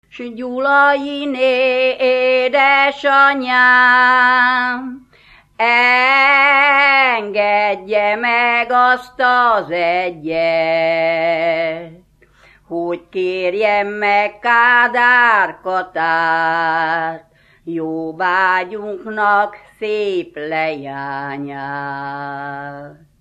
Erdély - Szolnok-Doboka vm. - Magyardécse
ének
Műfaj: Ballada
Stílus: 3. Pszalmodizáló stílusú dallamok
Szótagszám: 8.8.8.8
Kadencia: 7 (b3) b3 1